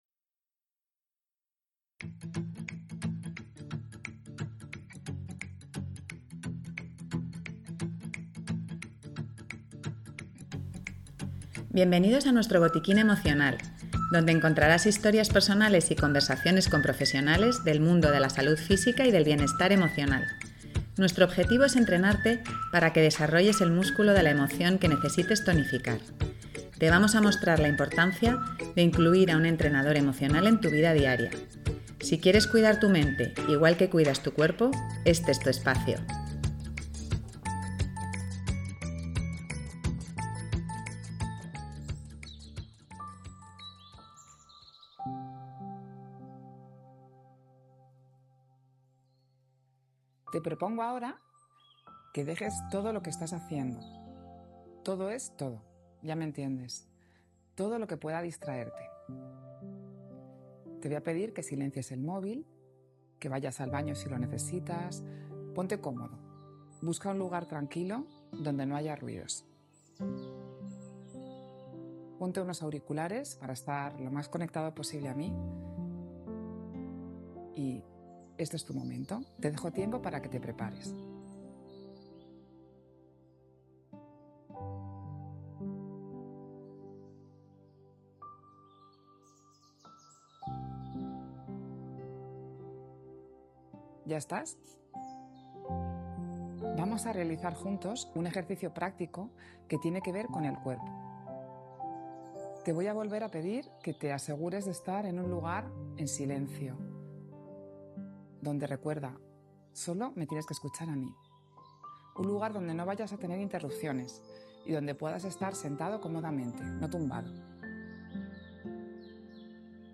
Busca un lugar donde nadie te moleste y puedas escuchar en calma las indicaciones de esta práctica de relajación. Identifica con nuestra ayuda las tensiones que puedas tener en tu cuerpo y suéltalas.